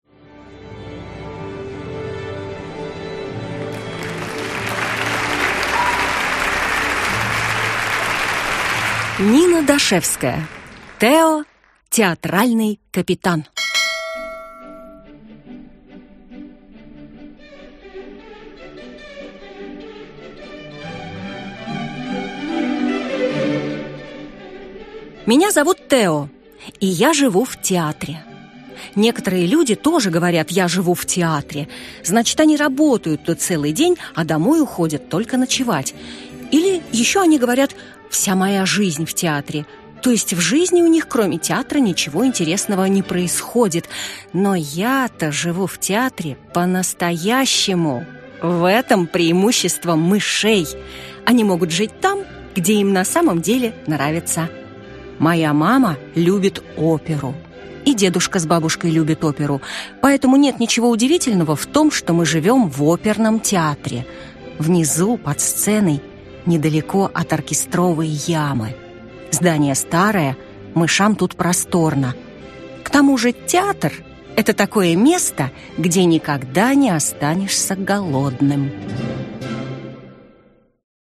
Аудиокнига Тео – театральный капитан | Библиотека аудиокниг